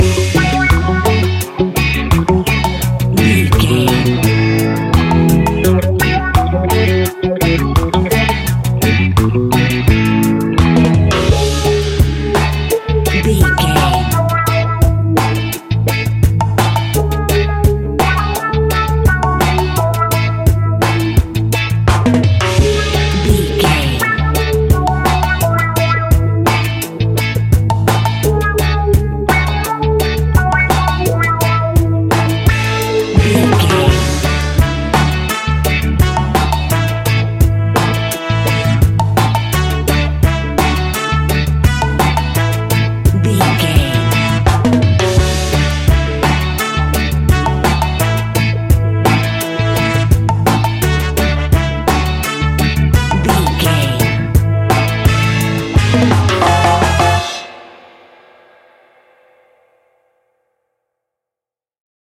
Classic reggae music with that skank bounce reggae feeling.
Uplifting
Aeolian/Minor
F#
laid back
off beat
drums
skank guitar
hammond organ
percussion
horns